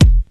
• Good Kick Drum Single Shot E Key 101.wav
Royality free kick drum tuned to the E note. Loudest frequency: 403Hz
good-kick-drum-single-shot-e-key-101-M9z.wav